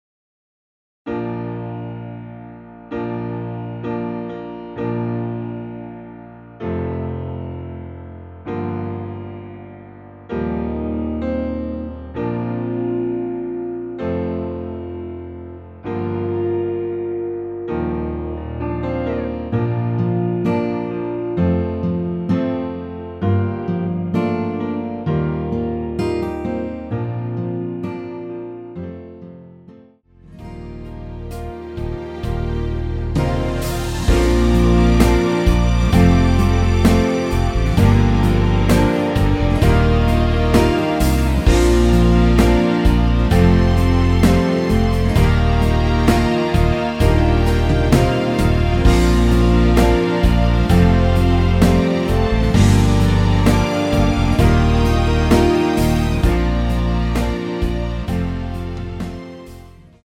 노래가 바로 시작 하는곡이라 전주 1마디 만들어 놓았습니다.(미리듣기 참조)
Bb
앞부분30초, 뒷부분30초씩 편집해서 올려 드리고 있습니다.
중간에 음이 끈어지고 다시 나오는 이유는